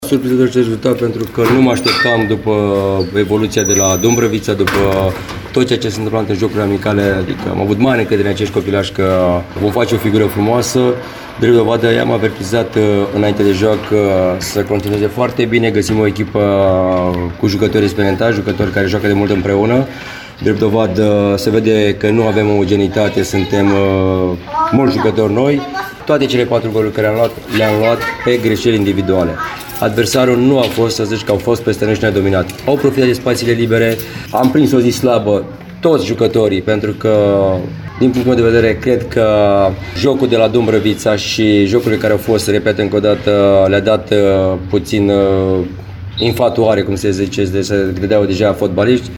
Ascultăm reacții culese după joc de colegul nostru